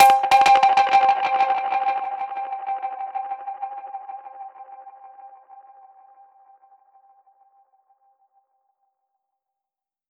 DPFX_PercHit_C_95-07.wav